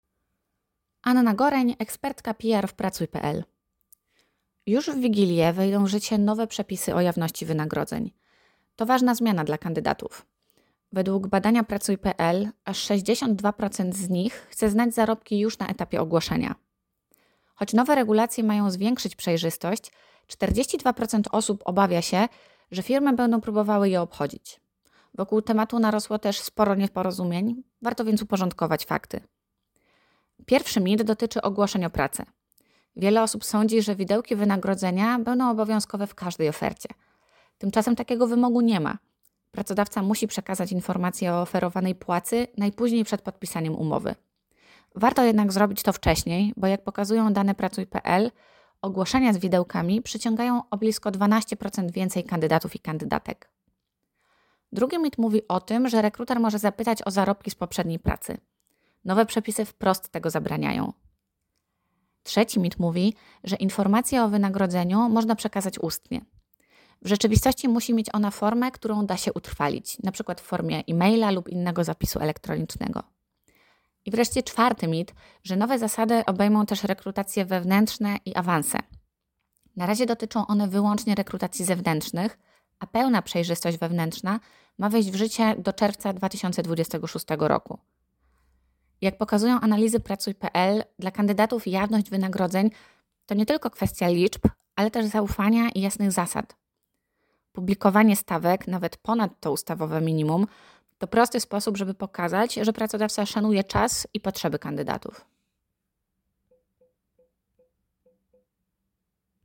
komentarza AUDIO